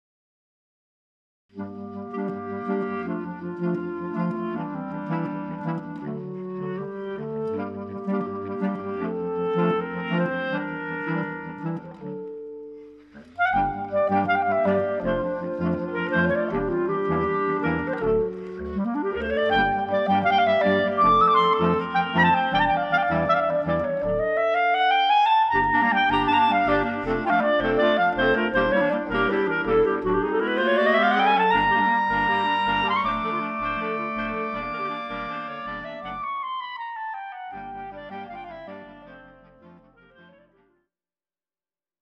Voicing: Clarinet Choir